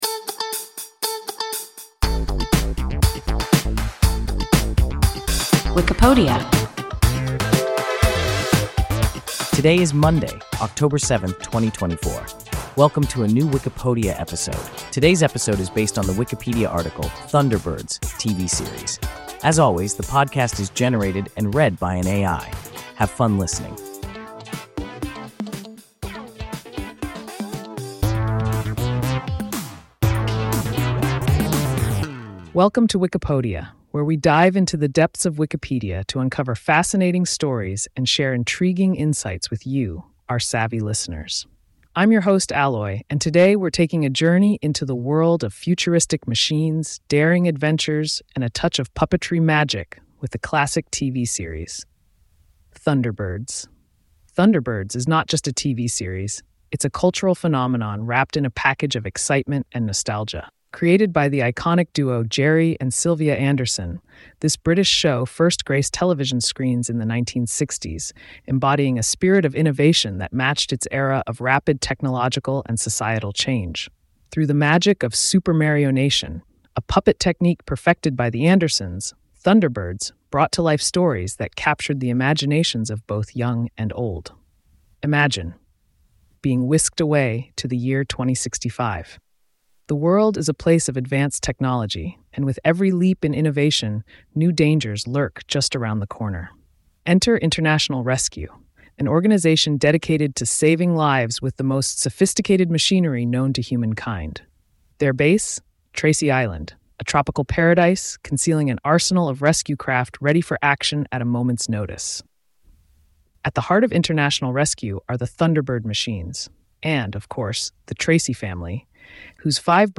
Thunderbirds (TV series) – WIKIPODIA – ein KI Podcast